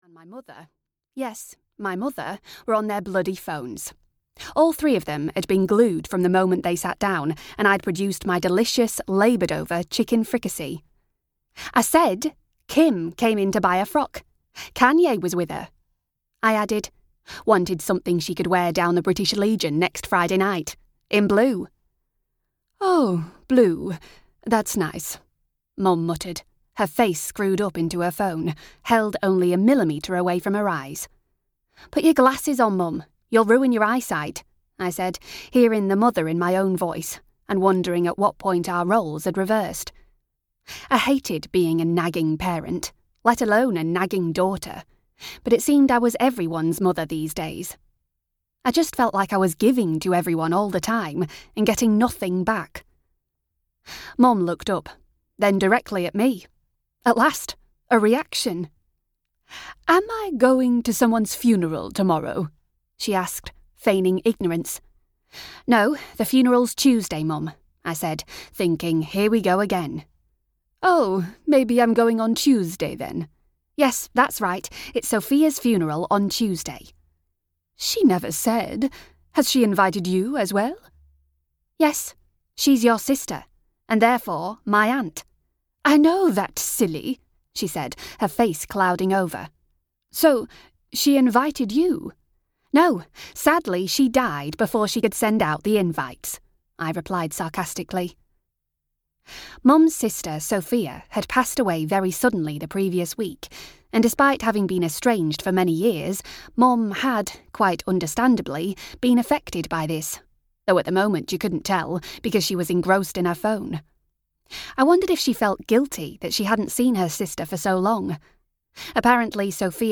Ella's Ice-Cream Summer (EN) audiokniha
Ukázka z knihy